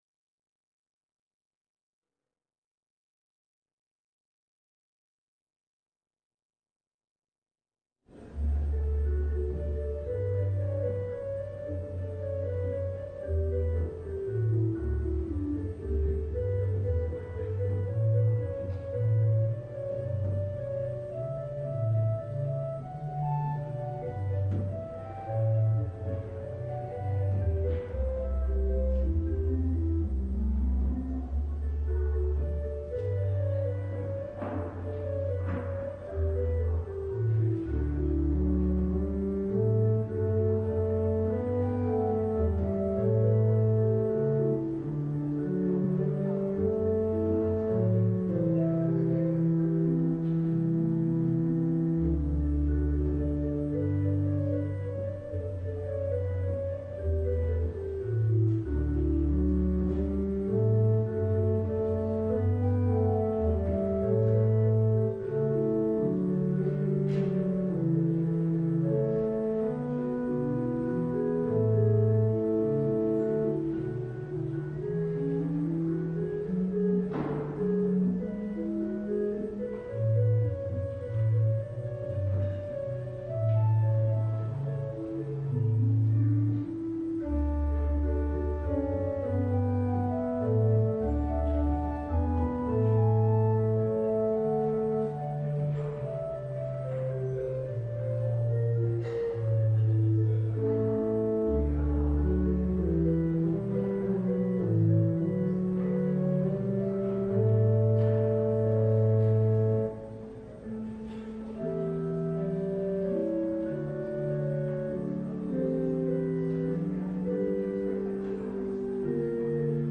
LIVE Morning Service - The Word in the Windows: Paying Taxes to Caesar